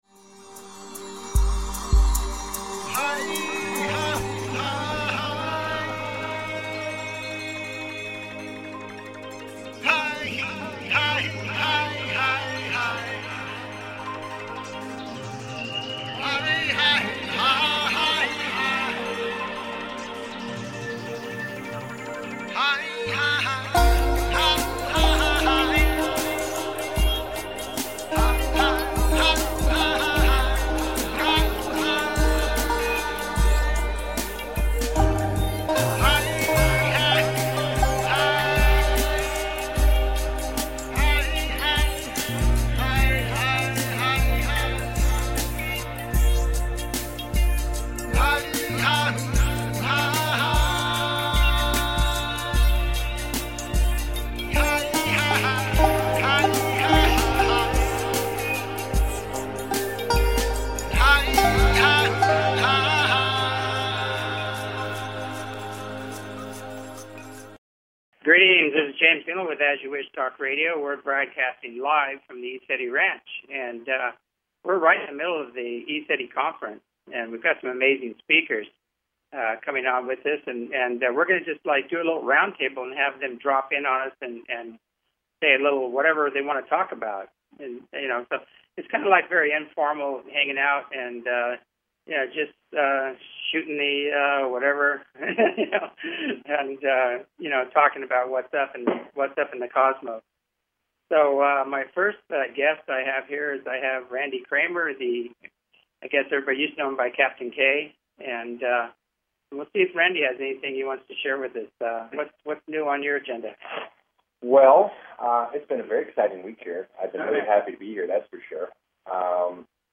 Talk Show Episode, Audio Podcast
Live from the field at ECETI Ranch